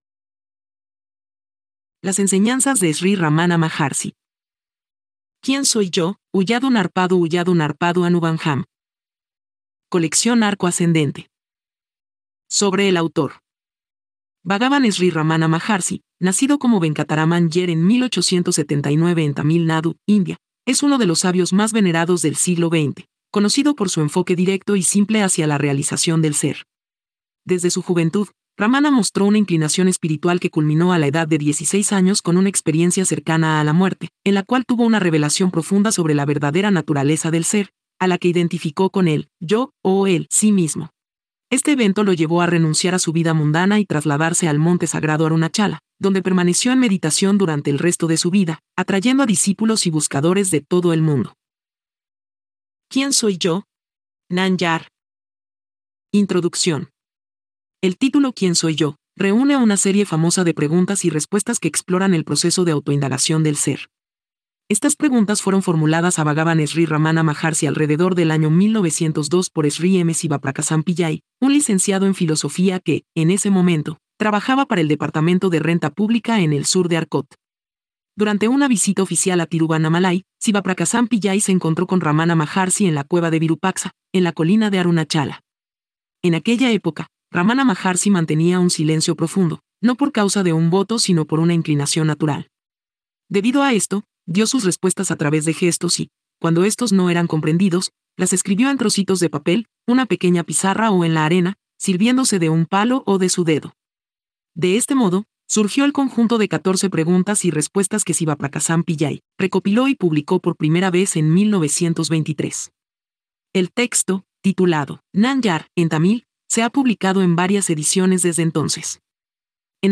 Lectura gratuita de prueba
Lectura-en-voz-alta-LAS-ENSENANZAS-DE-RAMANA-MAHARSHI.mp3